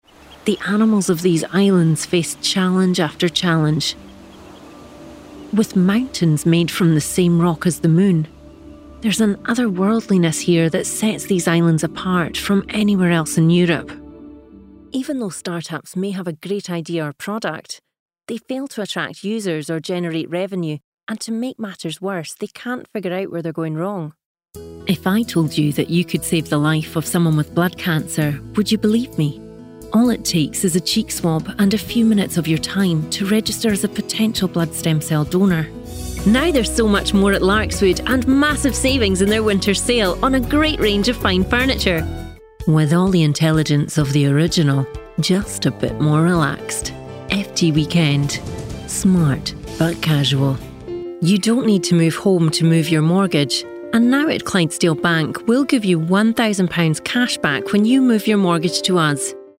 schottisch
Sprechprobe: Sonstiges (Muttersprache):